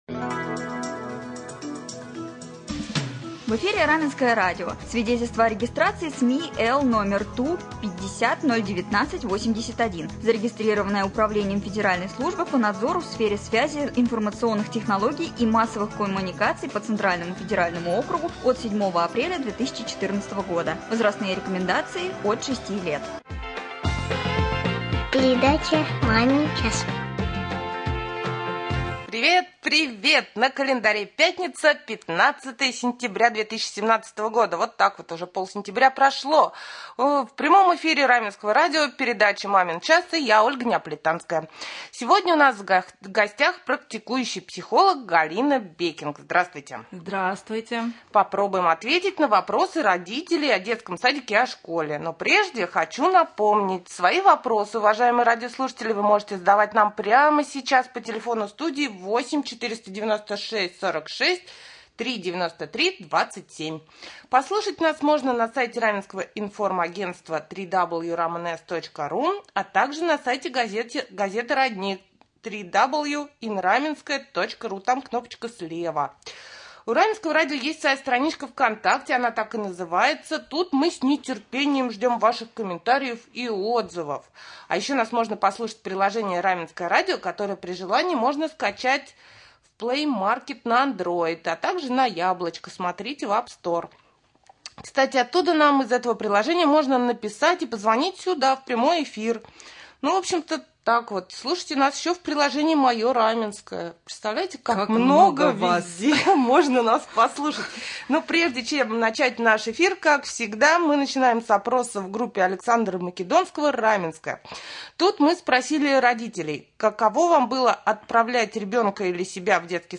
Гость студии